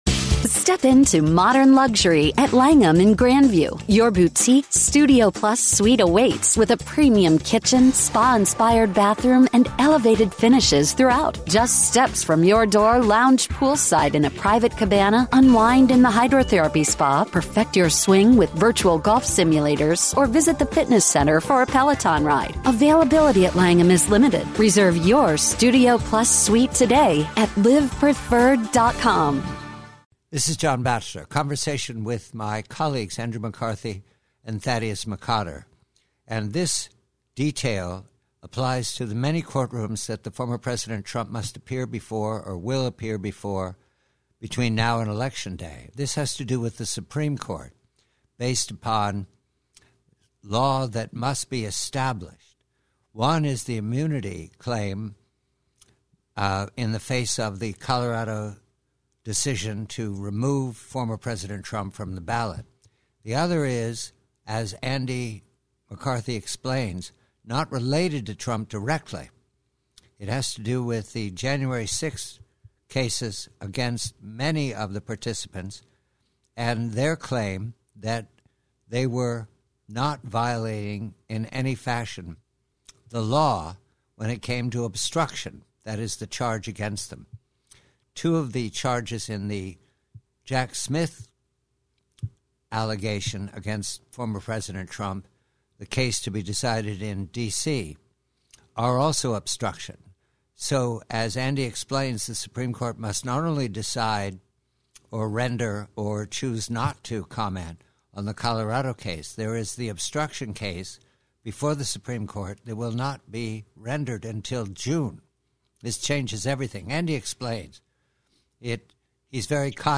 PREVIEW: Excerpt from conversation with Andrew McCarthy of NRO about the many courtrooms former President Trump has before him before the nomination process and/or the Election -- and a case that is now before the Supreme Court looks to be an explanation